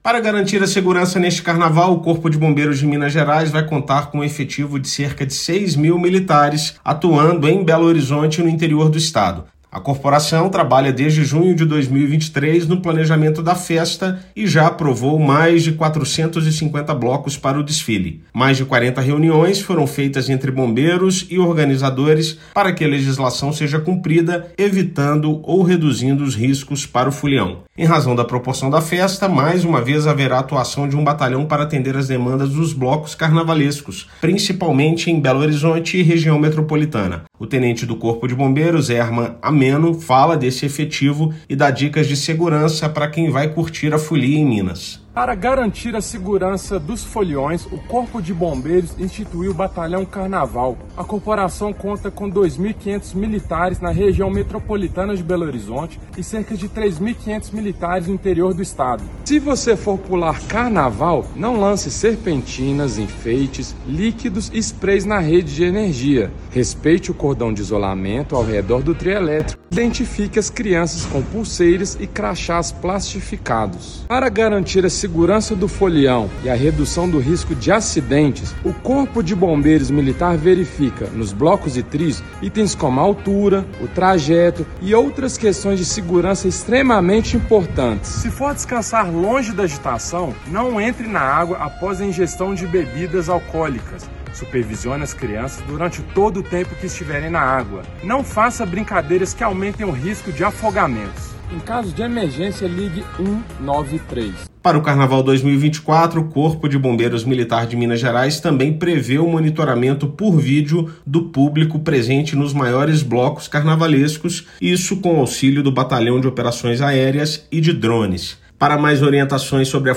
Preparação para garantir uma festa segura em todo o estado começa ainda no ano anterior e conta com equipe específica; cerca de 6 mil bombeiros vão trabalhar na capital e interior durante todo o feriado. Ouça matéria de rádio.